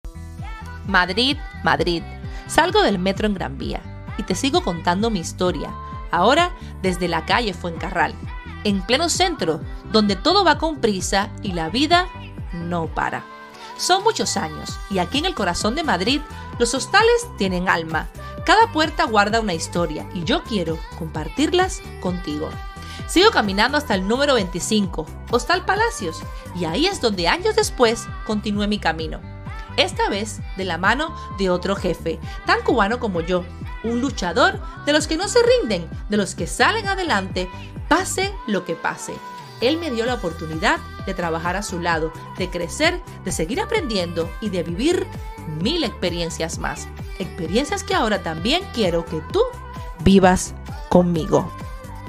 salgo del metro en Gran Vía y te sigo contando mi historia, ahora desde la calle Fuencarral, en pleno centro, donde todo va con prisa y la vida no para.